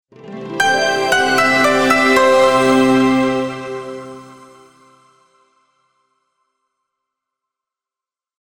tune opening